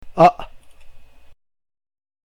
This is pronounced the way you would pronounce the sound between the letters C and D in the English word CUD.